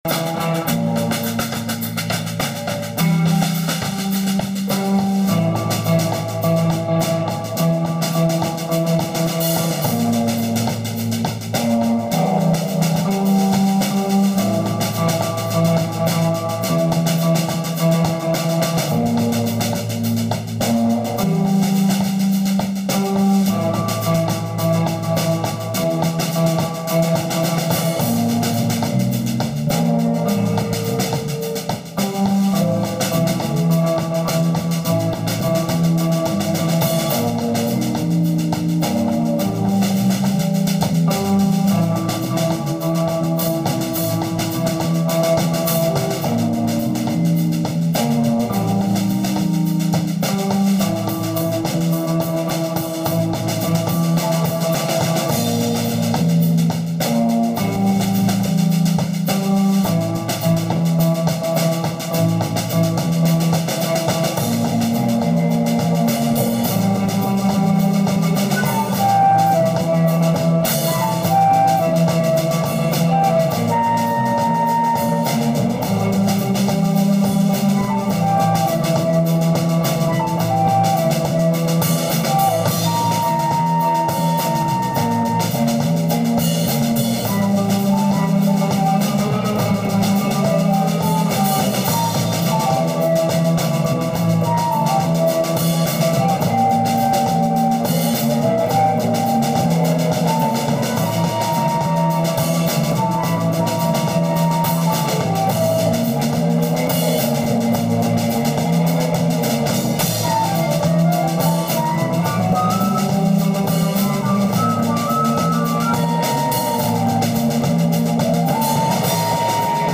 ALL MUSIC IS IMPROVISED ON SITE
Presybyterrier voice/guitar
flute
drums
sitar